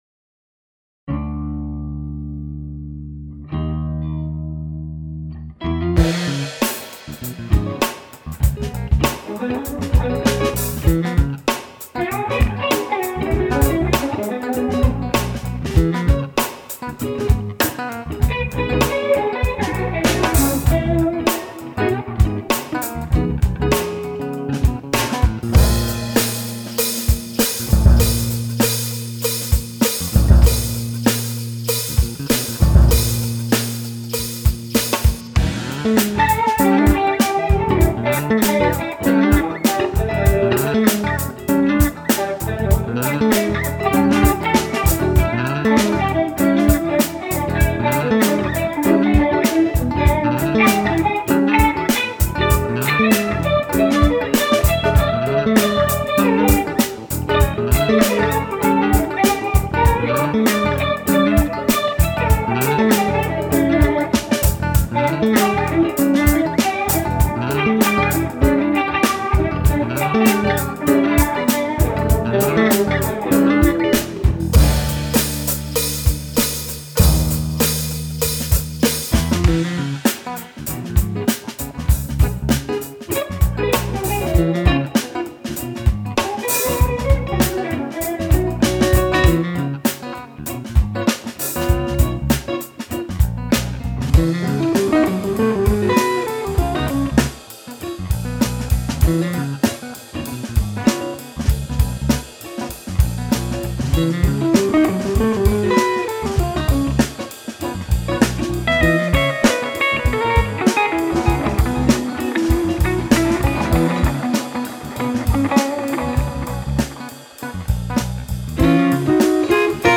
drums
instrumental jam tune